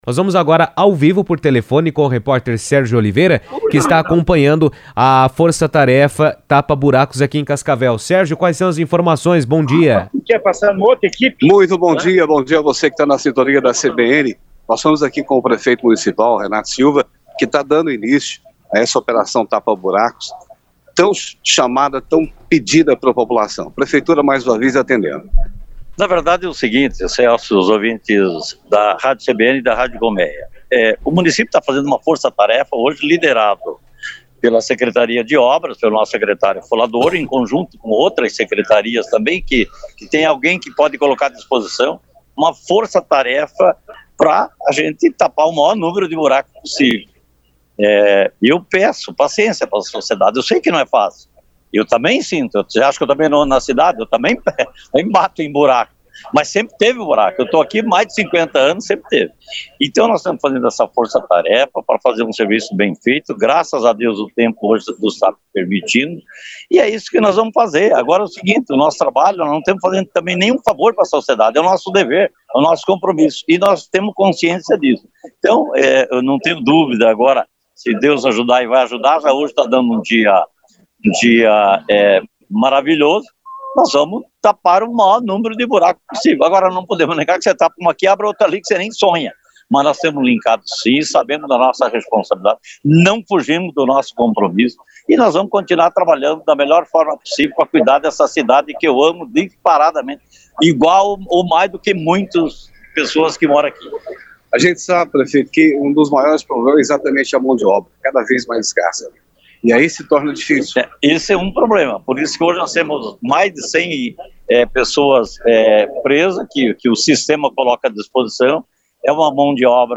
Nesta terça-feira (18), Cascavel realiza uma grande força-tarefa de tapa-buracos, mobilizando cerca de 50 trabalhadores para manutenção das vias da cidade. Em participação ao vivo por telefone na CBN, o prefeito Renato Silva e o secretário de Obras, Severino Folador, detalharam a operação, destacando que os bairros Country, São Cristóvão, Brasília, Floresta, Região do Lago, Maria Luíza, Cascavel Velho, Cataratas, Morumbi, Parque São Paulo e Neva receberão os trabalhos nesta etapa, com os demais bairros atendidos na sequência.